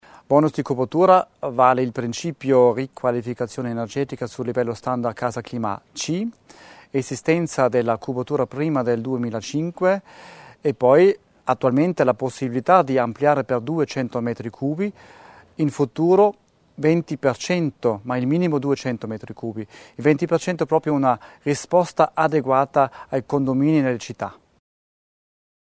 L'Assessore Laimer sul bonus cubatura